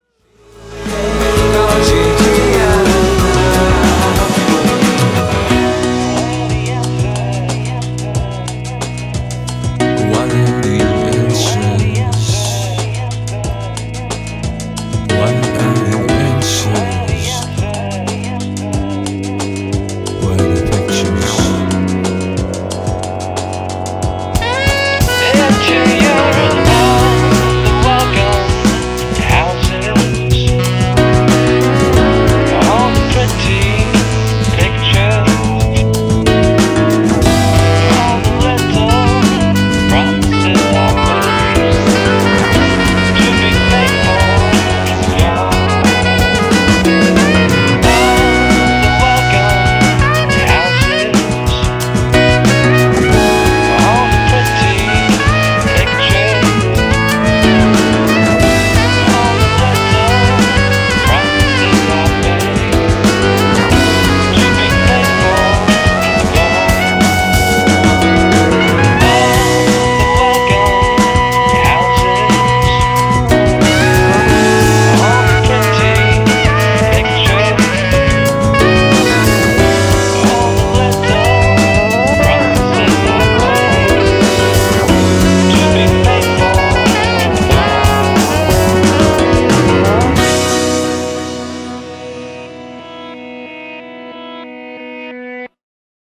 색소폰 솔로 부분
구간 자르고 시작점에 페이드인 삽입하고 플래쉬로도 만들었습니다.